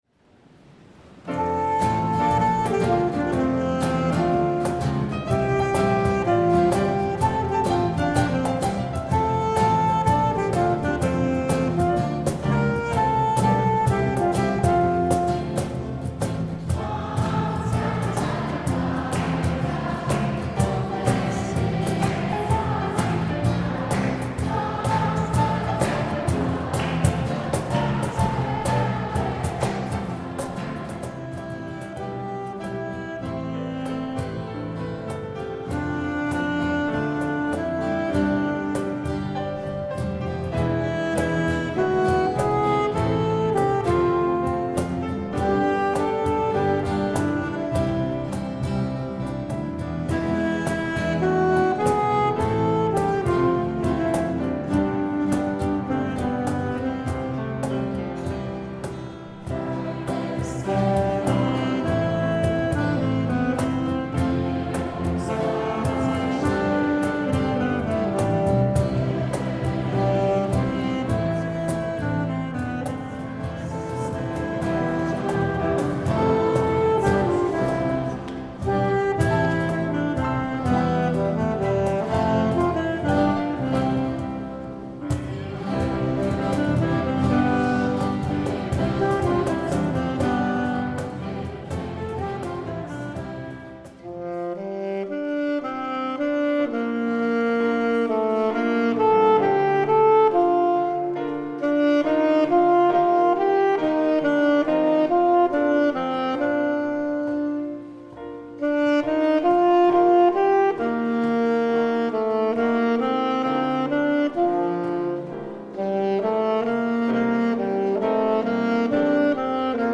Feierliche Erstkommunion